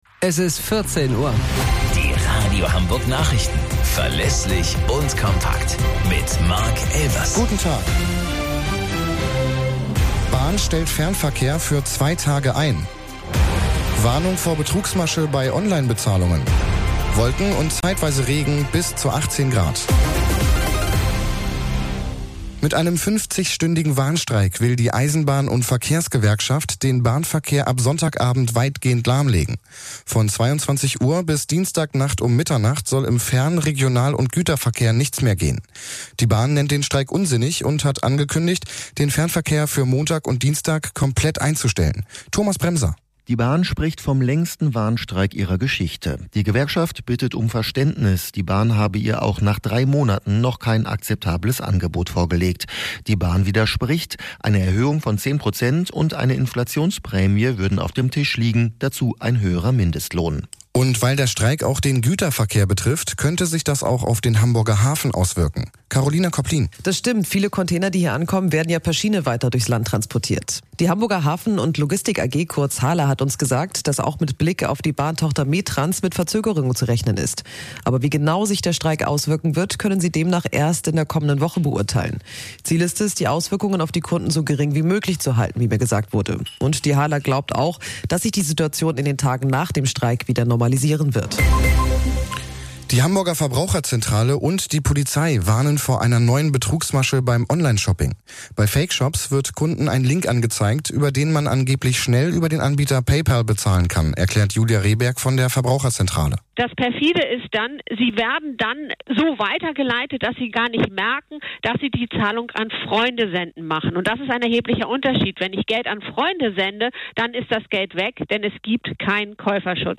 Radio Hamburg Nachrichten vom 19.07.2023 um 14 Uhr - 19.07.2023